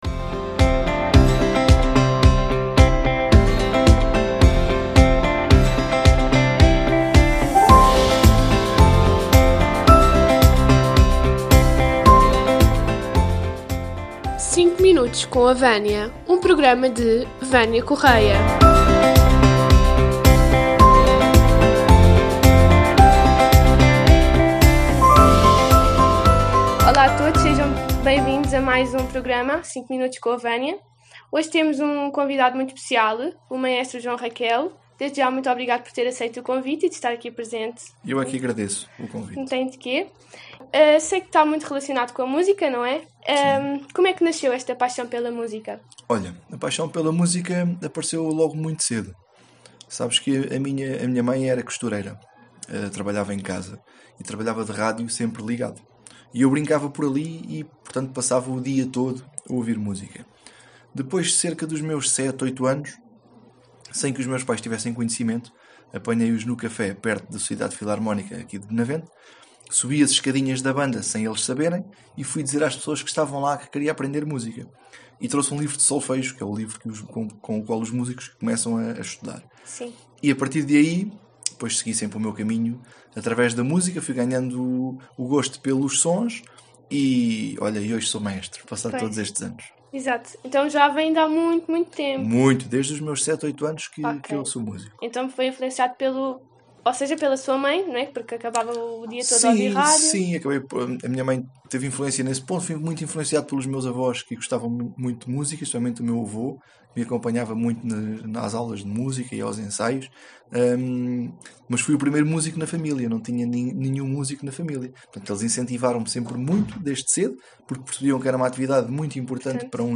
Podcast do Agrupamento de Escolas de Benavente